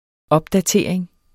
Udtale [ ˈʌbdaˌteˀɐ̯eŋ ]